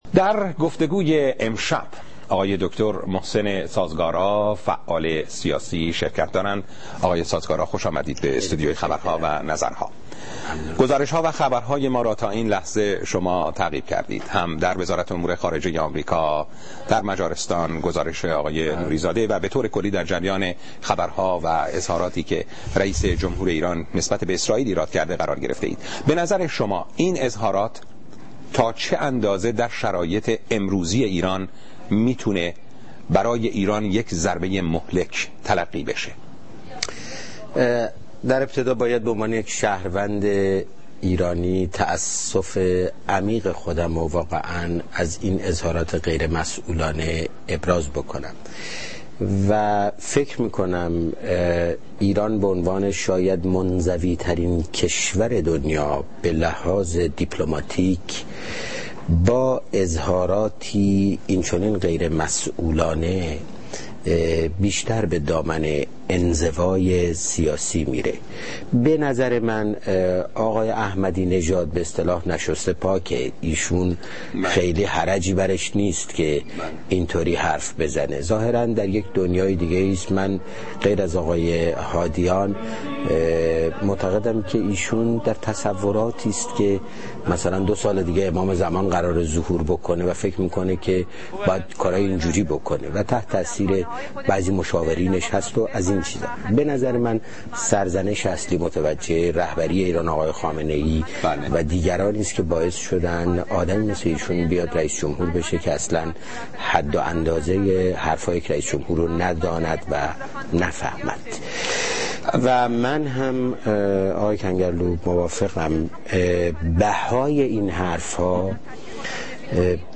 محسن سازگارا - محسن سازگارا در برنامه "خبرها و نظرها" از تلويزيون صدای آمريکا
مصاحبه ها